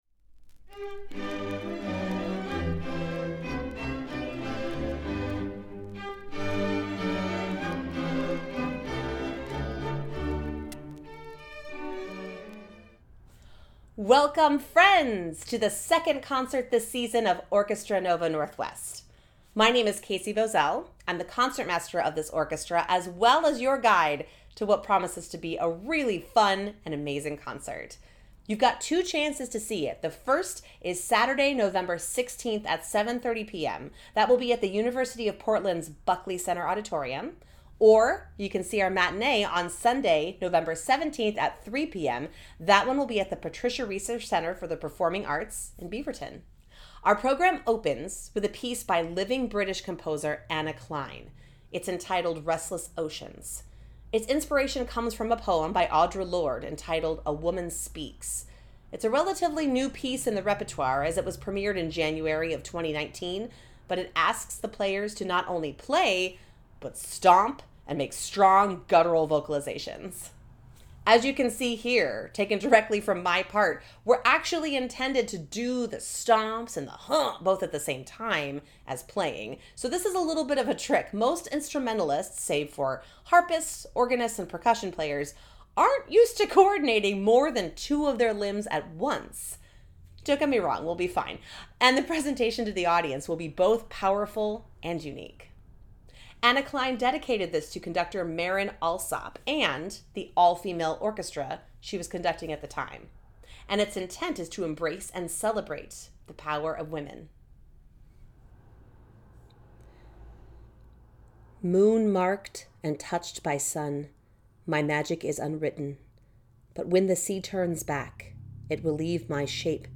Restless Pre-Concert Talk | Orchestra Nova Northwest
ONN-Pre-Concert-Talk-2.mp3